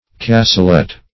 Search Result for " cassolette" : The Collaborative International Dictionary of English v.0.48: Cassolette \Cas`so*lette"\, n. [F.] a box, or vase, with a perforated cover to emit perfumes.